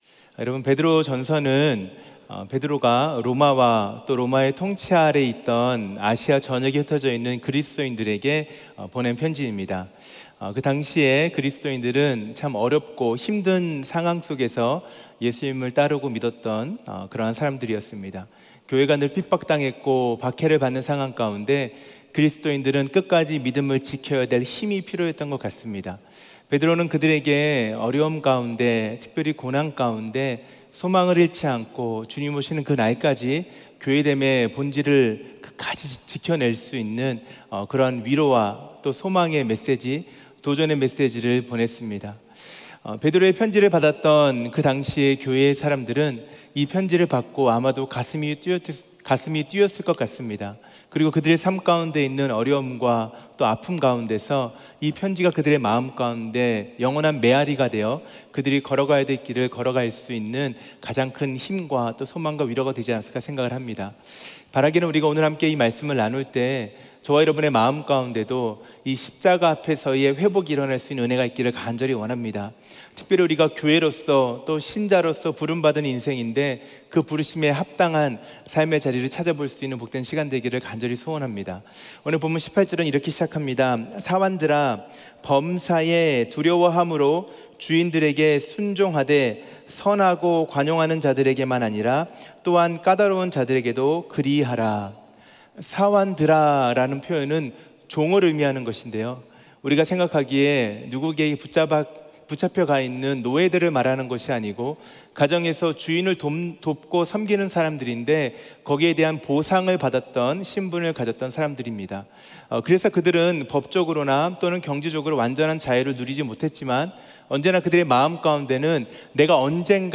2024년 고난주간 특별새벽기도회 넷째날 | 십자가로 회복 – 부르심 (3/28/2024)
예배: 특별 집회